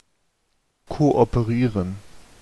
Ääntäminen
IPA: /ˌkoʔɔpəˈʀiːʀən/